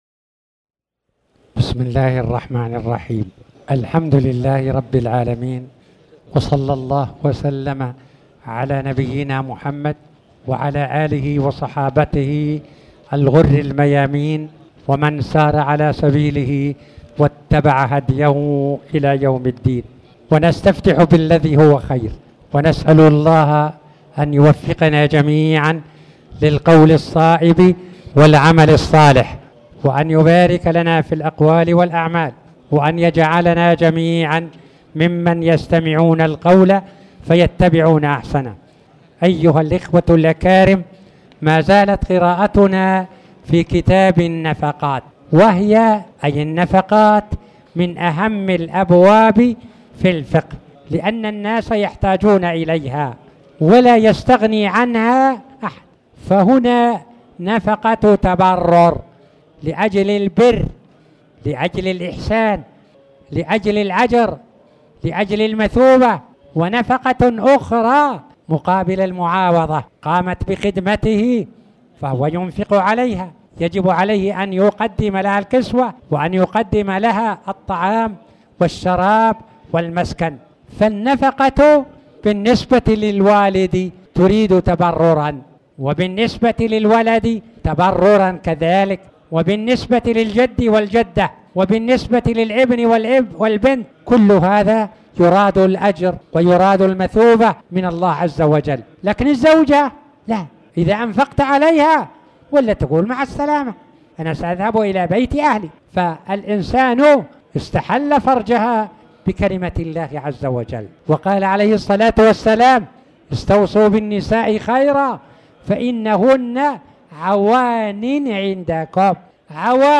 تاريخ النشر ٢ جمادى الآخرة ١٤٣٩ هـ المكان: المسجد الحرام الشيخ